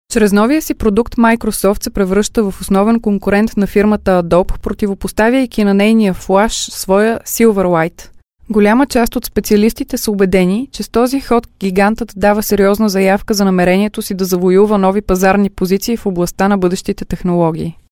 Sprecherin bulgarisch für Werbung, TV, Hörfunk, Industrie, Podcast
Sprechprobe: Industrie (Muttersprache):
Professional female bulgarian voice over artist